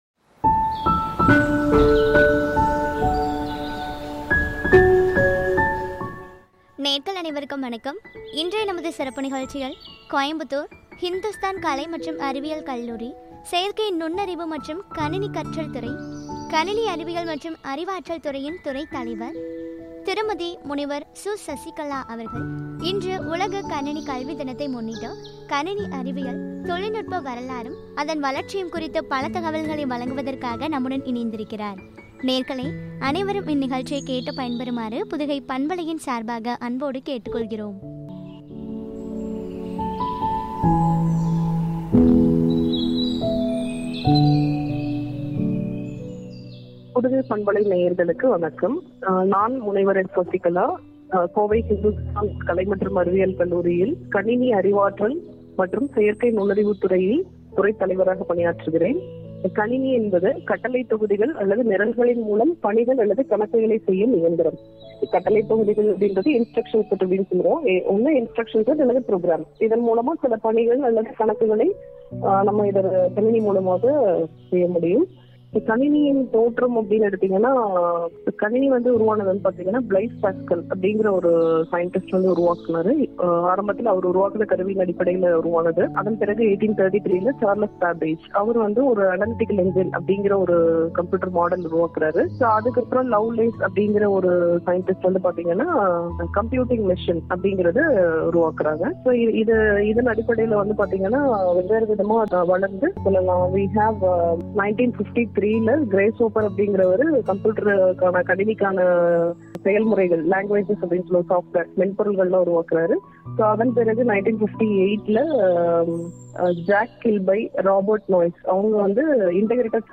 கணினி அறிவியல் & தொழில்நுட்பம் வரலாறும், வளர்ச்சியும் பற்றிய உரை.